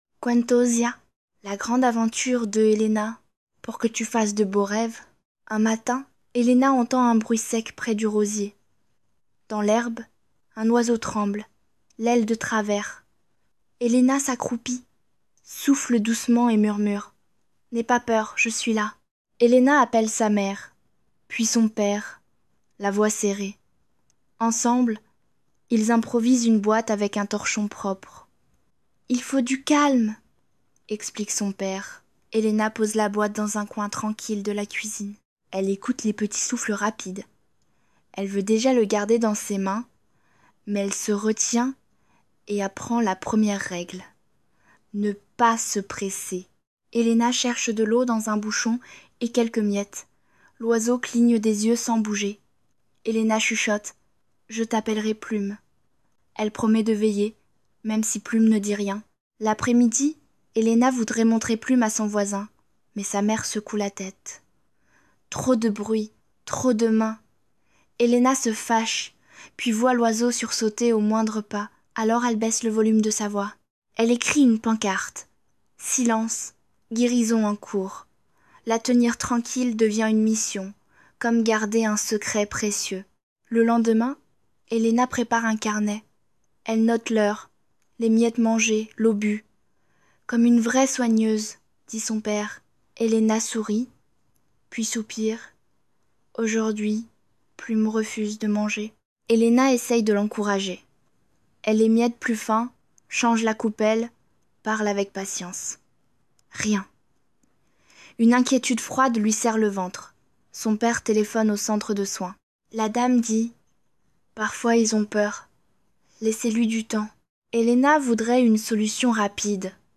Livre Audio Narré